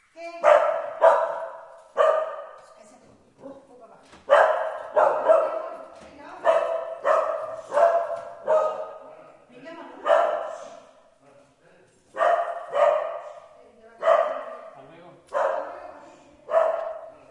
描述：一只小狗在楼梯上吠叫，一个女人说着什么。奥林巴斯LS10内置话筒
标签： 现场记录 踏着带
声道立体声